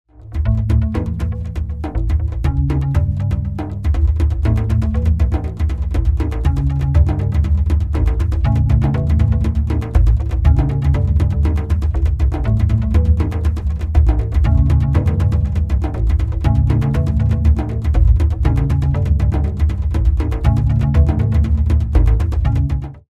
A Modern Dance Choreography CD
18 Instrumental Compositions  /  various orchestrated moods.